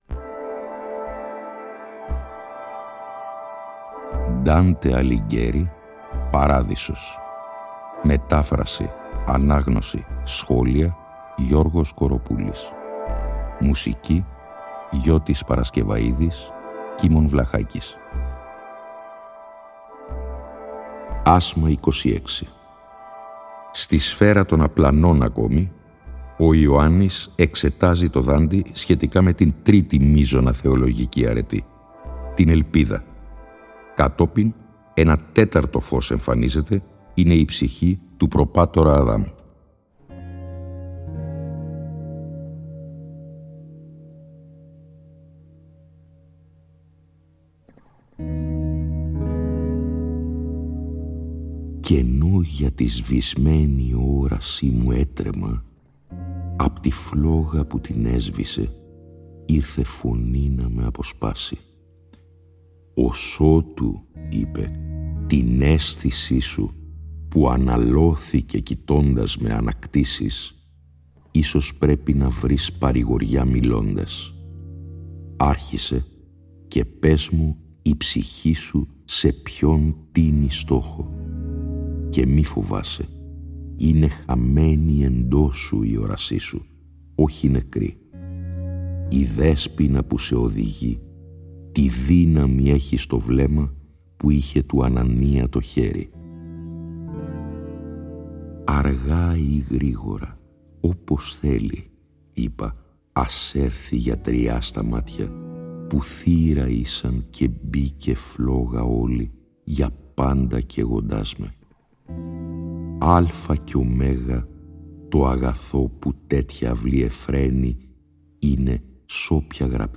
Η ανάγνωση των 33 ασμάτων του «Παραδείσου», σε 21 ημίωρα επεισόδια, (συνέχεια της ανάγνωσης του «Καθαρτηρίου», που είχε προηγηθεί) συνυφαίνεται και πάλι με μουσική
Η μετάφραση τηρεί τον ενδεκασύλλαβο στίχο και υποτυπωδώς την terza rima του πρωτοτύπου – στο «περιεχόμενο» του οποίου παραμένει απολύτως πιστή.